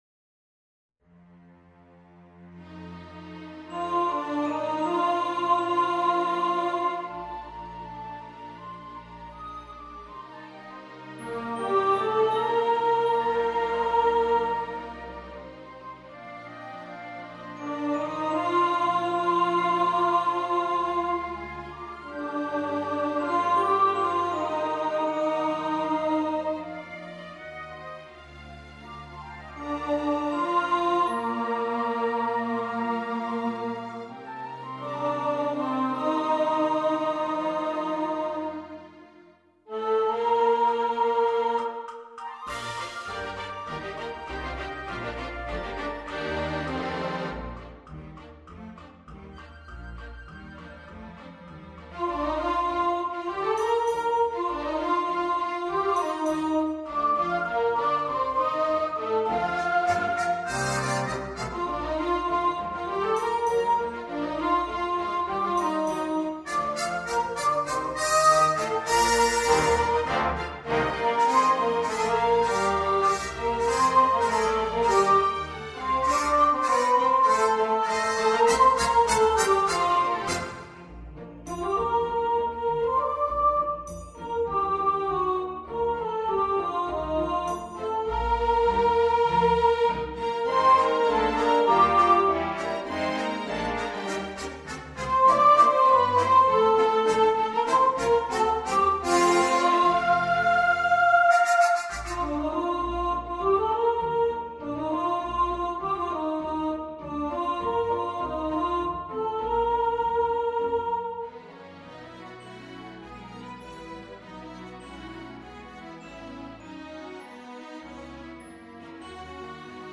Santa Claus Is Coming To Town Soprano | Ipswich Hospital Community Choir